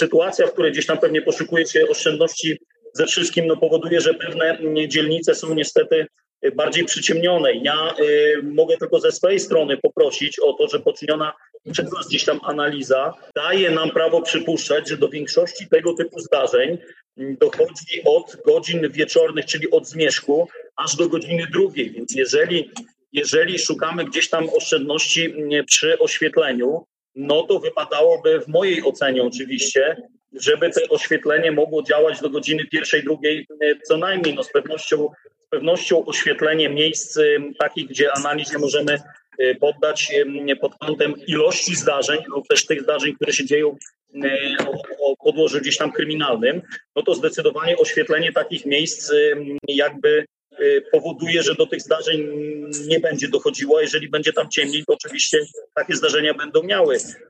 Na ostatniej sesji Rady Miejskiej rajcy pytali, czy i jak to się ma do bezpieczeństwa. Podinspektor Bartosz Lorenc, komendant miejscowej jednostki policji wyjaśniał, że do większości przestępstw dochodzi od zmierzchu do drugiej w nocy.